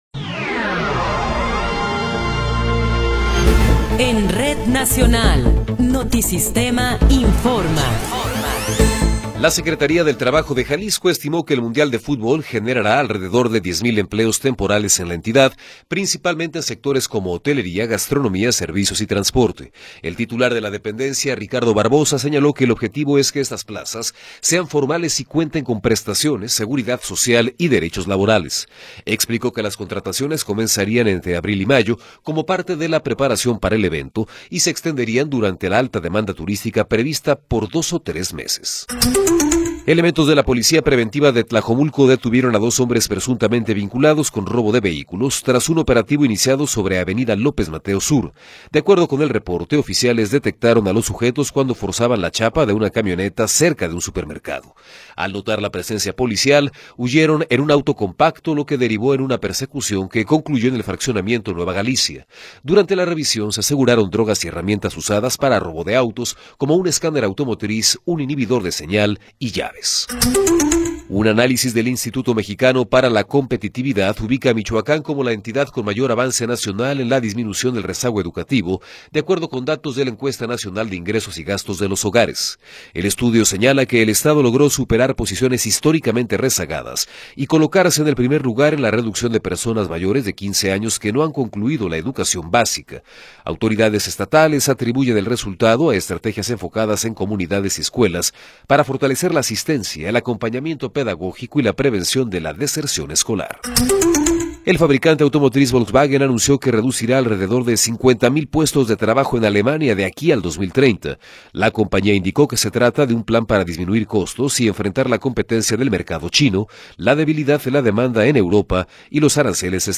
Noticiero 18 hrs. – 14 de Marzo de 2026